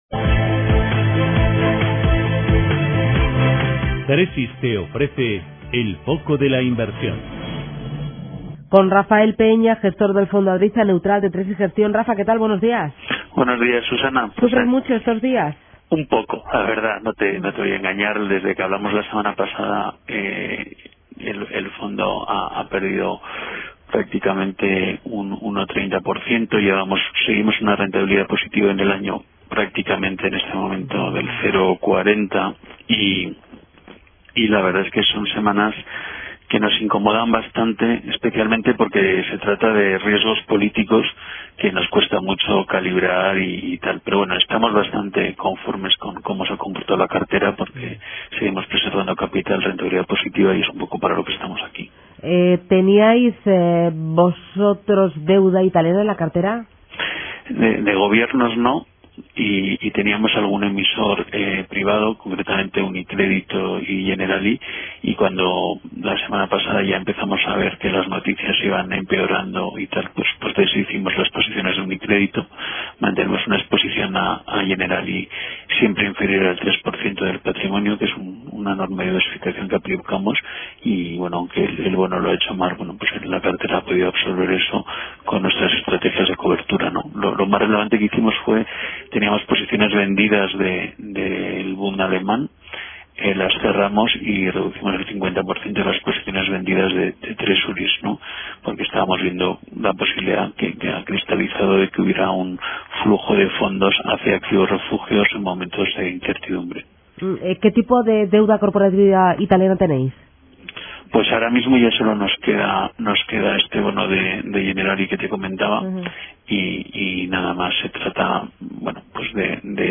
En Radio Intereconomía todas las mañanas nuestros expertos analizan la actualidad de los mercados.